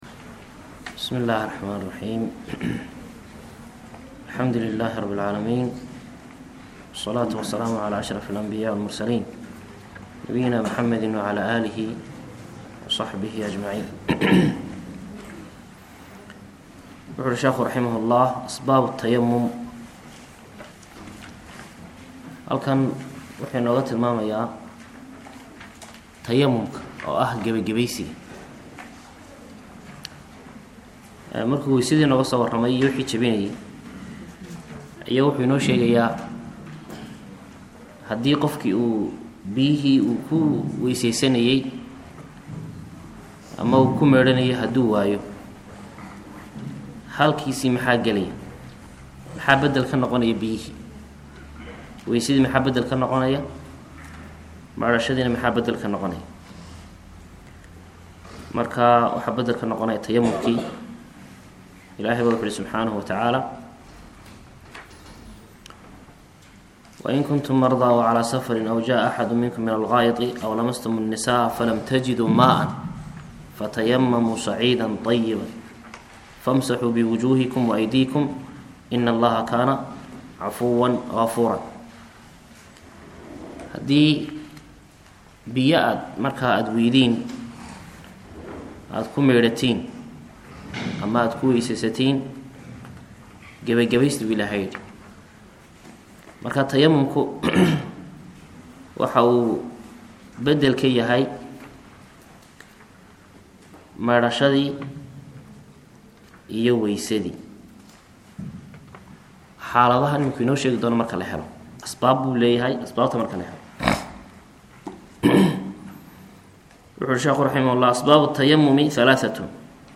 Duruus Taxane Ah Oo Ku Saabsan Fiqiga Mad-habka Imaam Ash-Shaafici